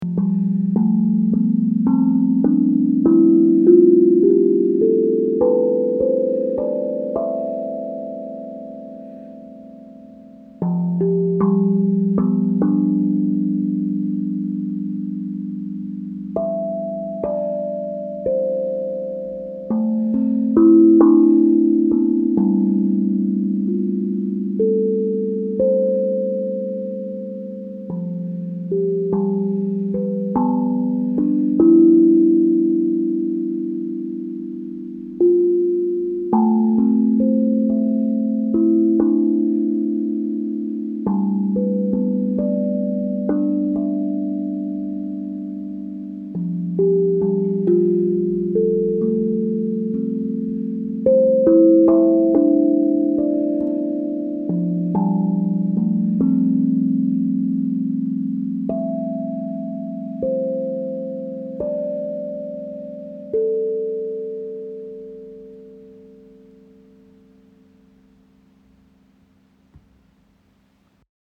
Tongue Drum • Do majeur • 14 notes
Le tongue drum, également appelé tank drum est un instrument de percussion mélodique qui produit des sons doux et apaisants.
14 Notes
Gamme Do majeur
do-majeur-14-tongue-drum-.mp3